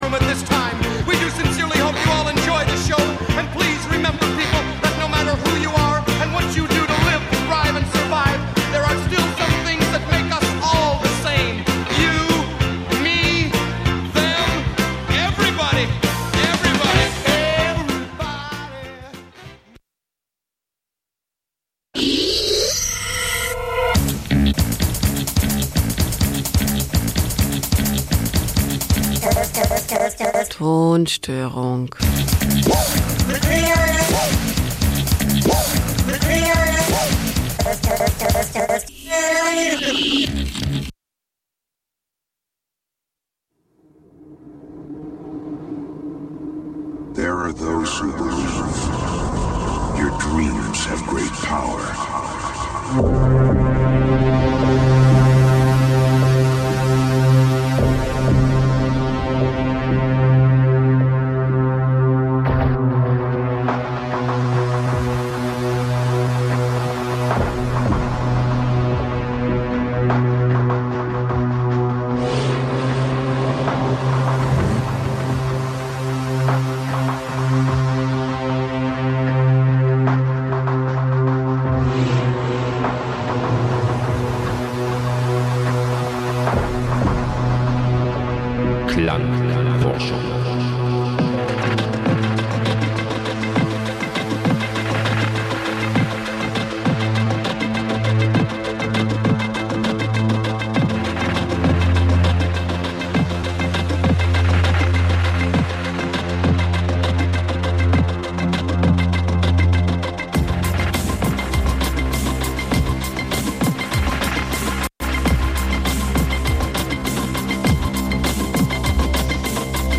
Abstrakte Soundwelten, nie geh�rte Stimmen und musikgewordene Filme f�r Dein Hirnkino, jenseits von Eurodance und Gitarrengeschrammel.
Das Spektrum der musikalischen Bandbreite reicht von EBM , Minimalelektronik, Wave ,Underground 80`s bis hin zu Electro ,Goth und Industriell.
Sendung für elektronische Musik Dein Browser kann kein HTML5-Audio.